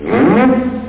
Amiga 8-bit Sampled Voice
SCRAPESN.mp3